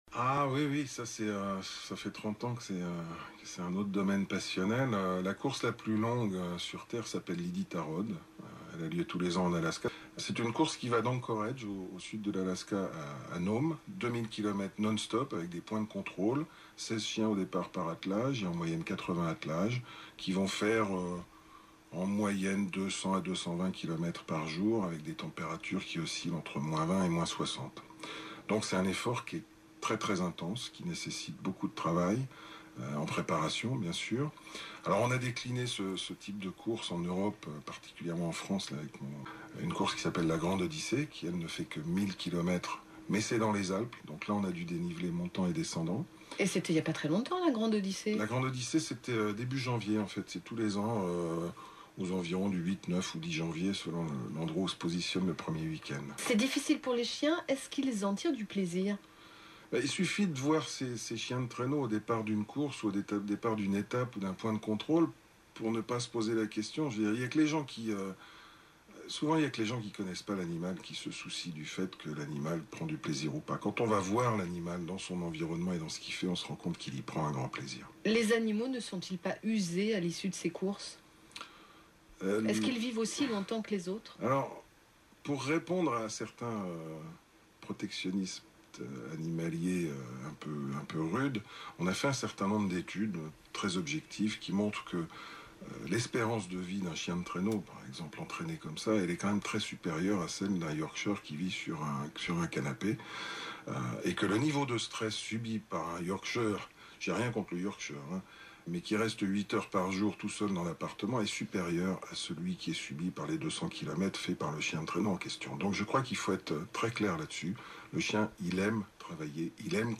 Pourtant, j’ai écouté avec plaisir, et tout à fait par hasard, ce vétérinaire parler de sa passion.